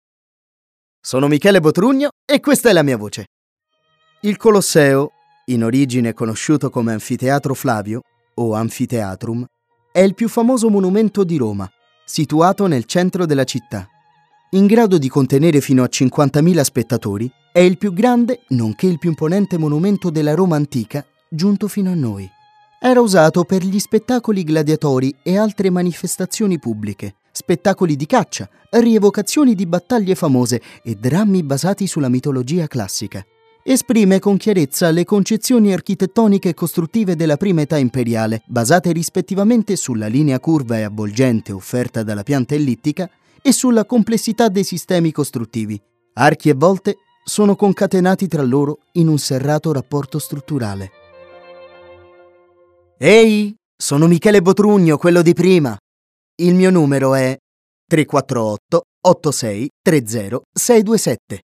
Speaker Italiano- doppiatore italiano- documentario italiano- voice over-radio-film
Kein Dialekt
Sprechprobe: Industrie (Muttersprache):